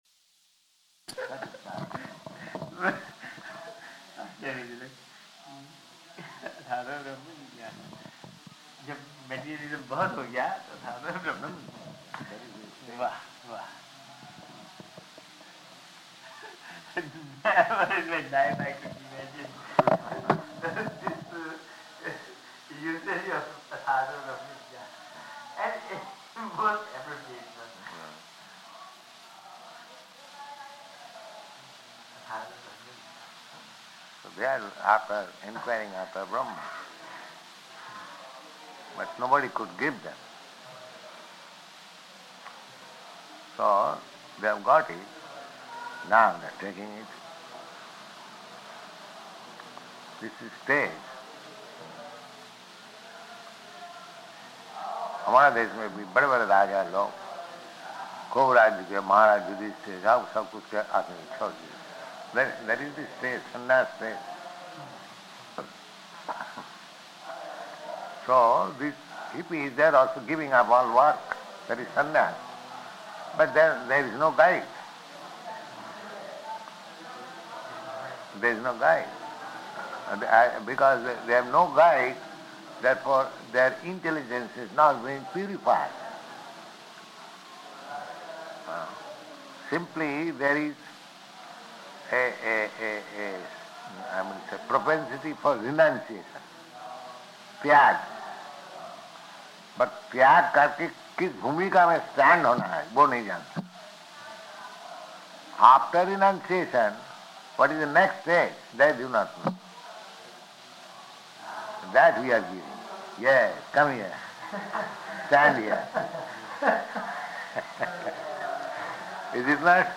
Room Conversation
Location: Surat
Audio file: 701217R1-SURAT.mp3